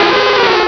cries
-Replaced the Gen. 1 to 3 cries with BW2 rips.